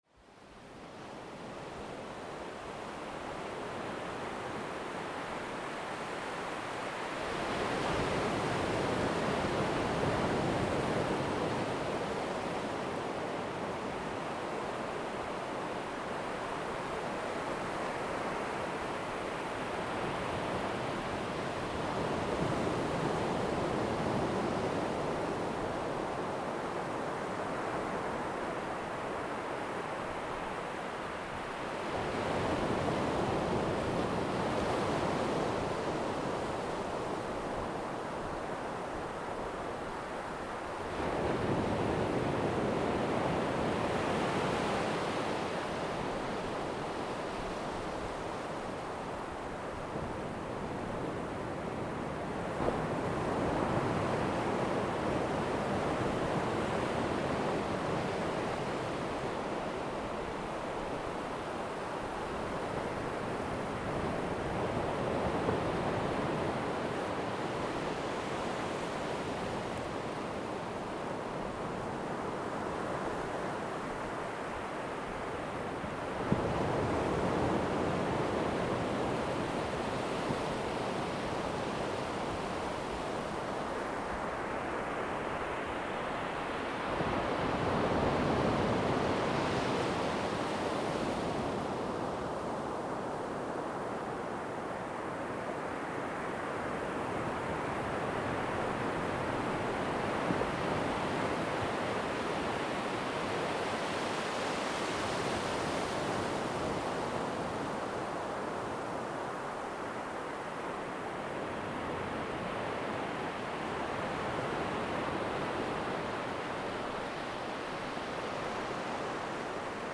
Here’s an example of the sound of waves he picked up at one of our photo-spots.
lofotengolven.mp3